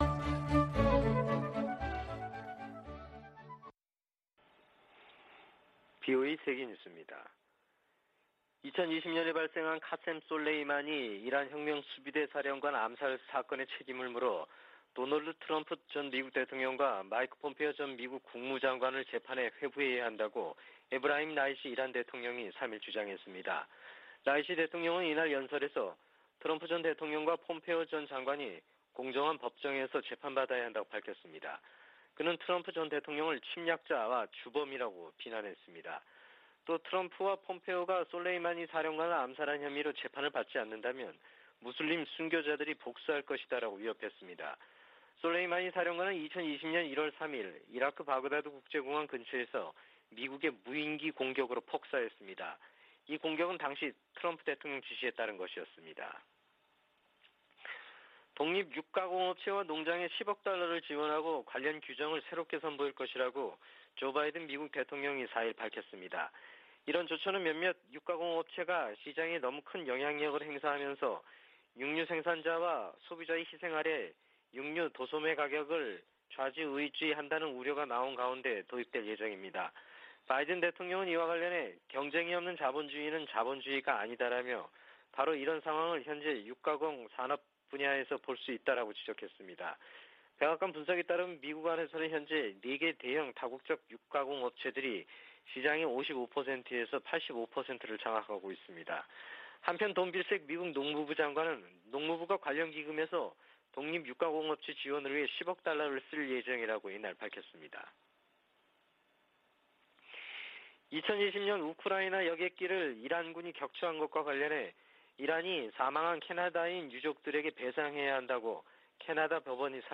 VOA 한국어 아침 뉴스 프로그램 '워싱턴 뉴스 광장' 2021년 1월 5일 방송입니다. 미 국무부 동아시아태평양국이 조 바이든 행정부 들어 대북 외교에서 역할을 복원 중이라는 감사보고서가 나왔습니다. 미국 정부가 북한에 대화 복귀를 거듭 촉구했습니다. 주요 핵무기 보유국들이 핵무기 사용에 반대하고, 핵확산금지조약(NPT)의 의무를 강조하는 공동성명을 발표했습니다.